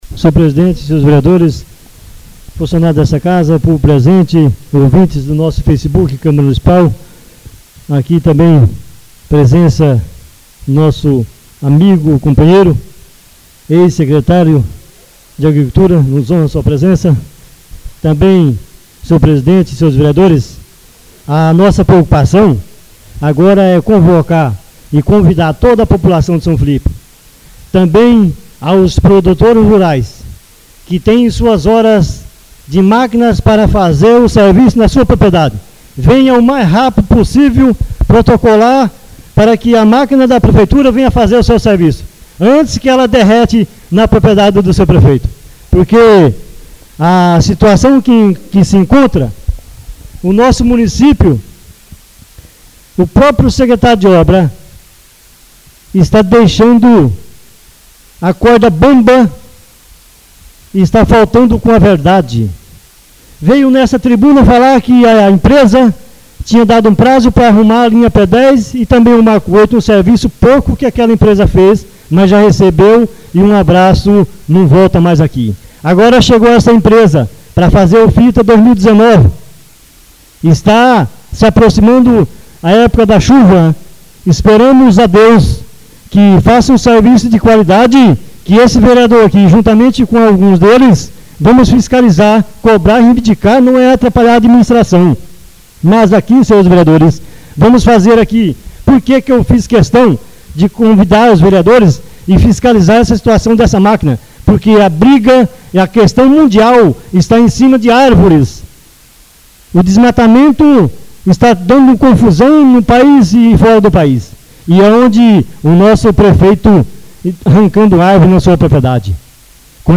Oradores das Explicações Pessoais (26ª Ordinária da 3ª Sessão Legislativa da 6ª Legislatura)